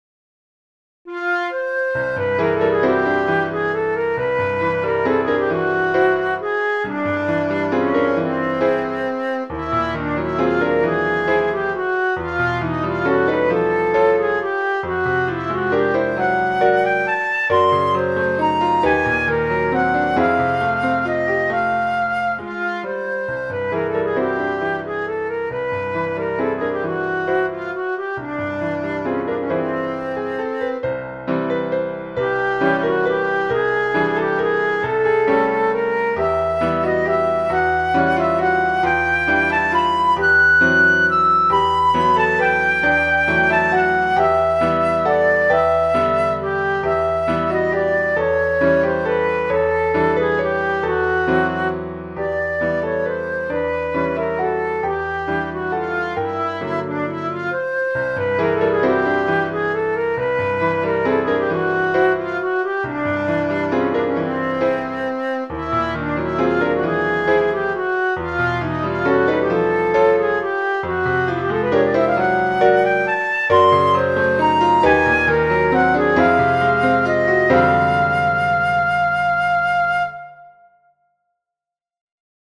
Three short compositions for flute and piano